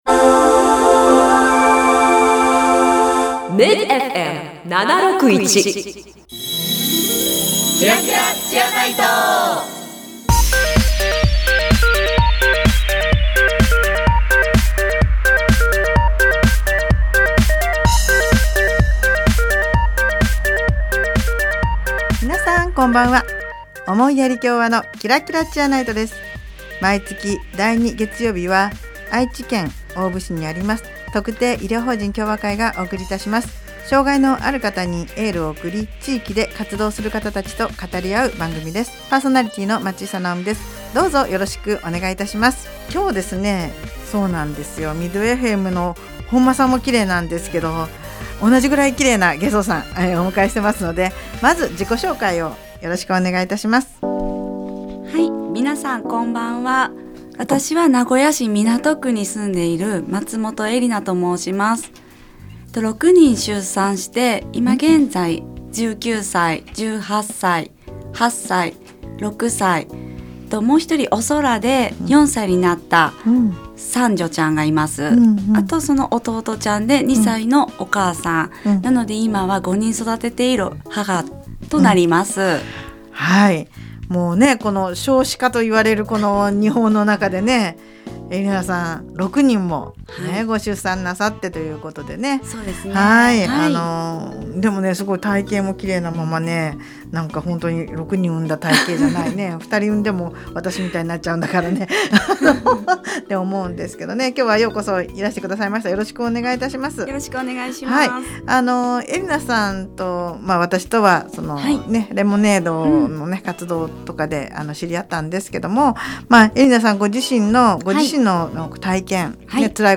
【放送時間 】第2月曜日 19：00 MID-FM 76.1
この番組では、地域の医療・福祉に携わる方々と語り合い、偏見にさらされやすい障がいのある方に心からのエールを送ります。 毎回、医療・福祉の現場に直接携わる方などをゲストに迎え、現場での色々な取り組みや将来の夢なども語り合います。